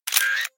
camera_click.ogg